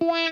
WAH SOLO 2.wav